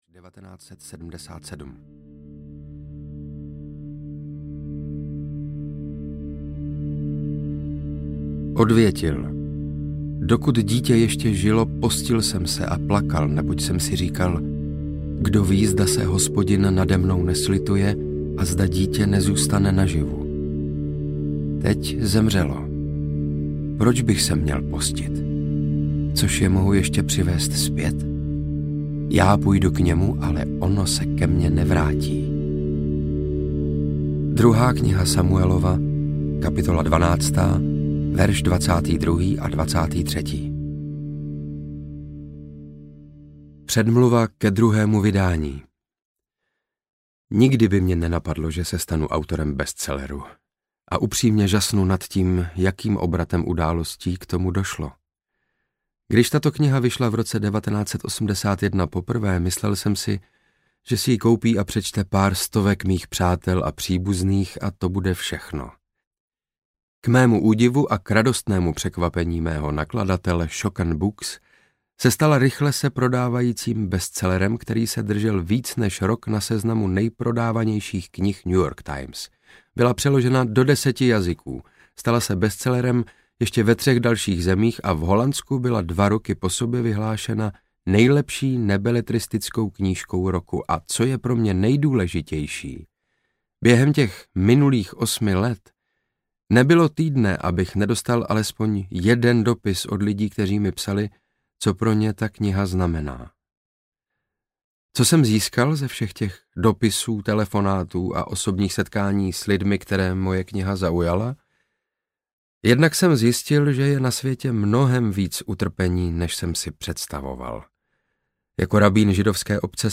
Když se zlé věci stávají dobrým lidem audiokniha
Ukázka z knihy
• InterpretSaša Rašilov